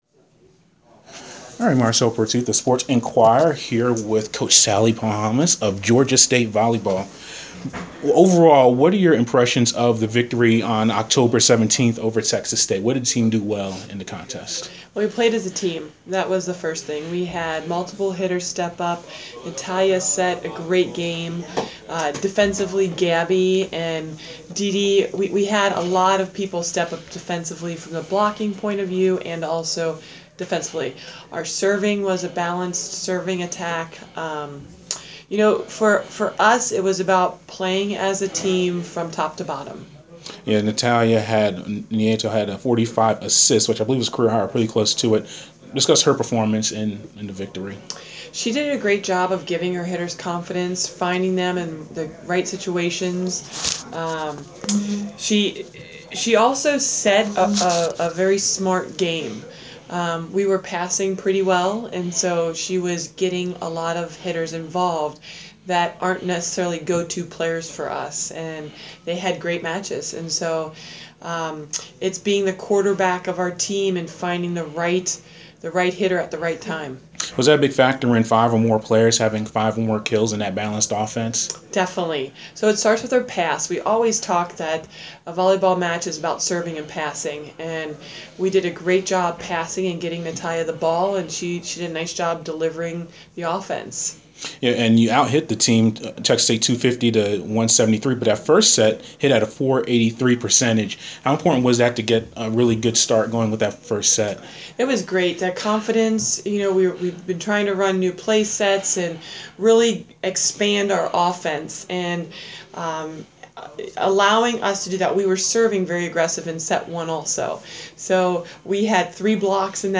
Interview
before practice on Oct. 21